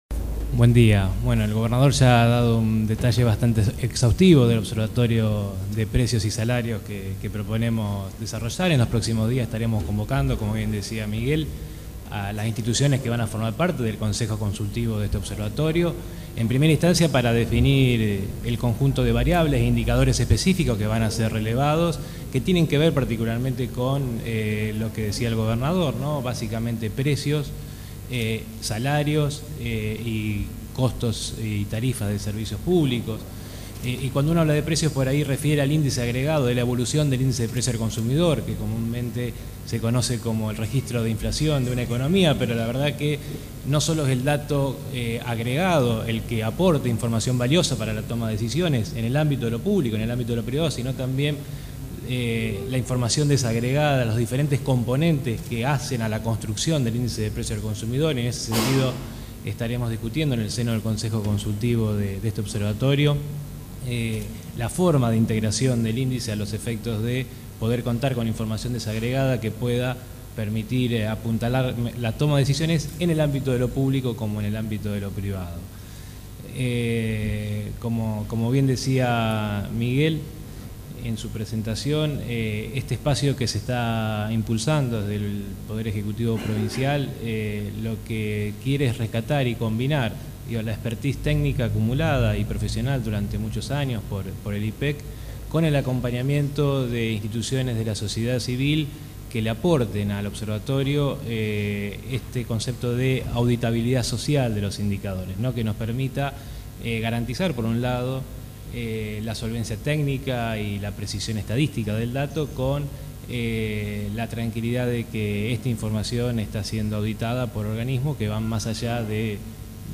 El gobernador de Santa Fe, Miguel Lifschitz, junto al ministro de Economía, Gonzalo Saglione, y el secretario de Finanzas, Pablo Olivares, anunció este martes la creación del Observatorio Estadístico Provincial (OEP) para la elaboración de estadísticas e índices de precios propios de la provincia, en una conferencia de prensa realizada en Casa de Gobierno de la ciudad de Santa Fe.